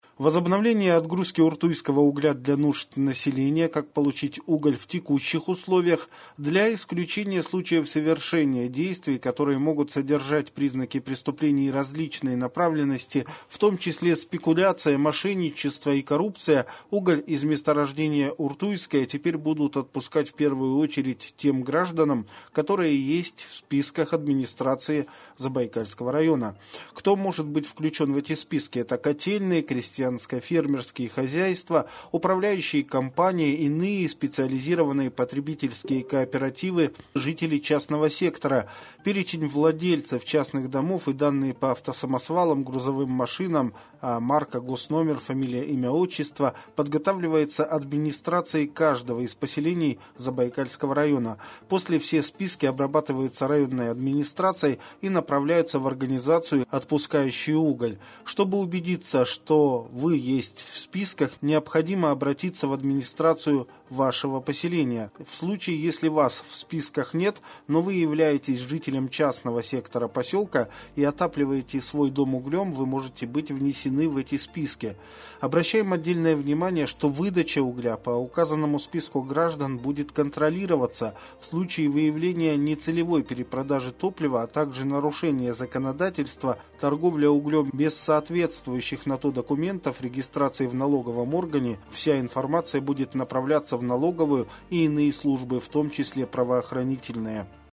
Аудиовариант новости !